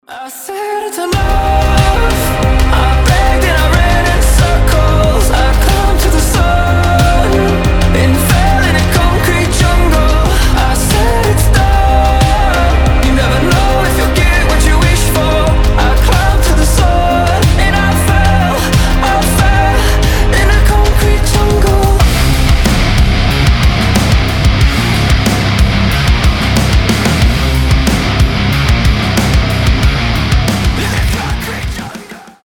• Качество: 320, Stereo
красивый мужской голос
Alternative Metal
Industrial rock
Growling